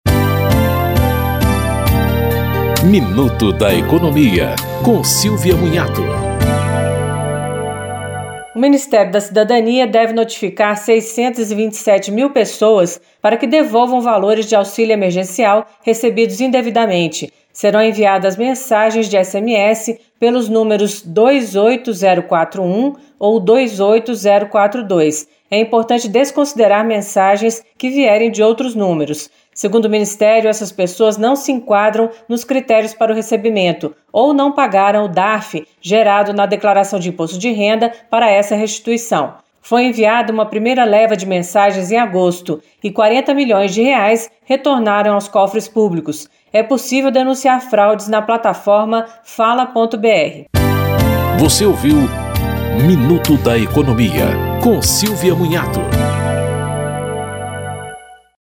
Em um minuto, dicas sobre direitos do consumidor, pagamento de impostos e investimentos.